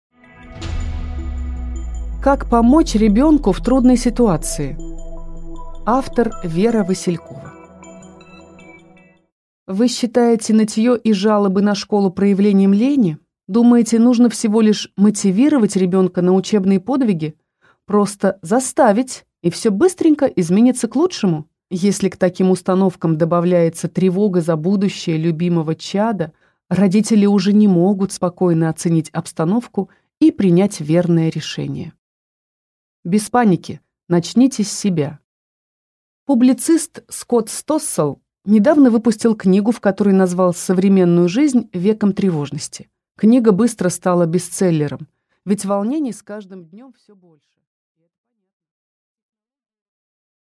Аудиокнига «Ненавижу школу!»
Прослушать и бесплатно скачать фрагмент аудиокниги